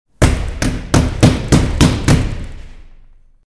Звуки стуков в дверь
Громкий стук в дверь